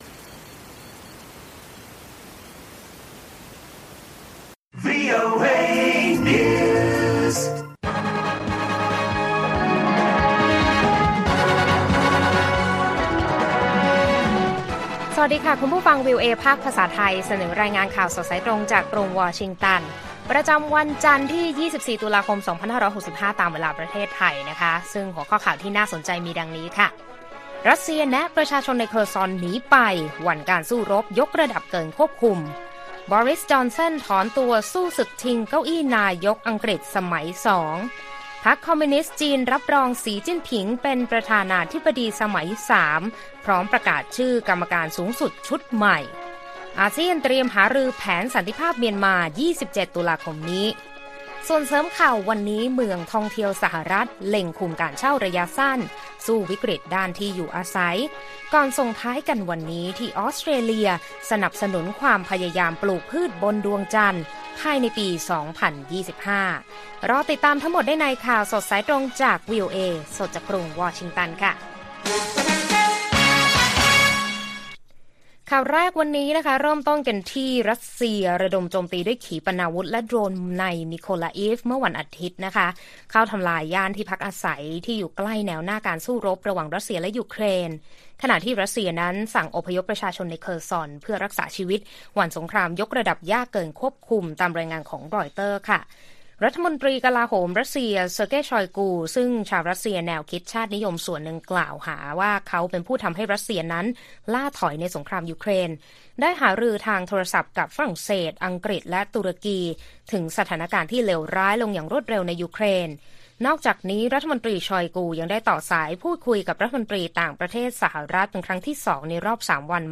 ข่าวสดสายตรงจากวีโอเอไทย วันจันทร์ ที่ 24 ต.ค. 2565